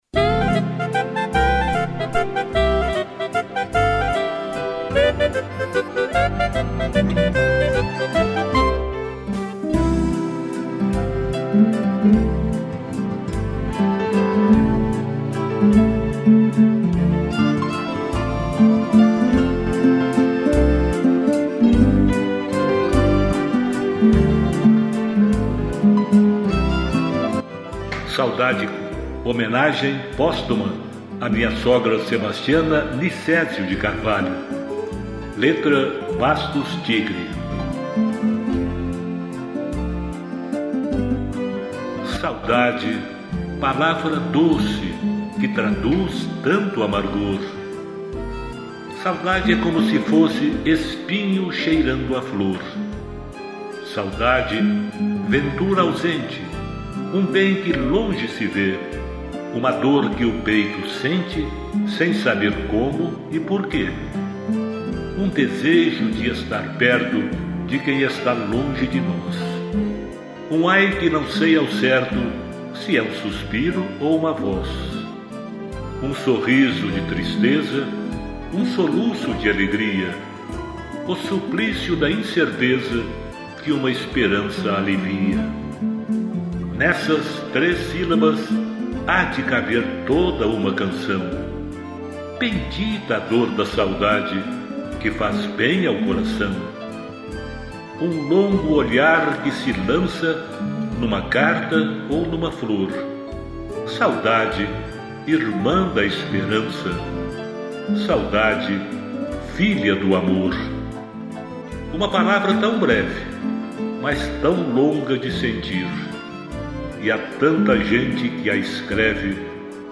piano, acordeom, cello e violino